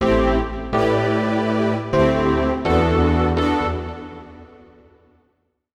SYNTH015_VOCAL_125_A_SC3(L).wav